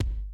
Index of /90_sSampleCDs/Zero-G Groove Construction (1993)/Drum kits/New Jack Swing/Kick